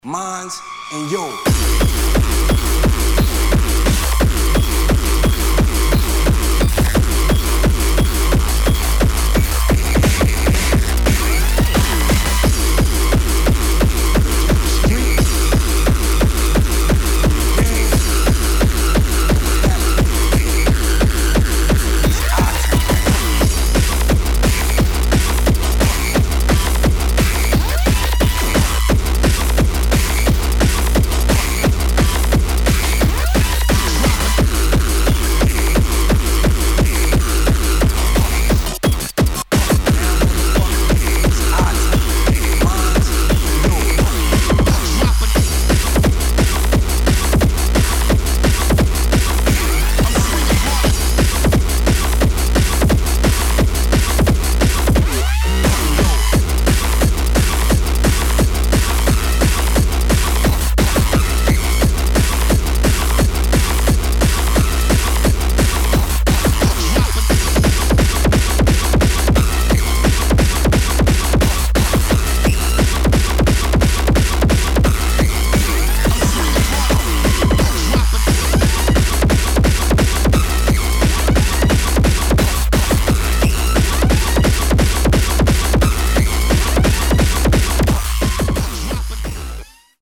[ HARDCORE / GABBA / INDUSTRIAL ]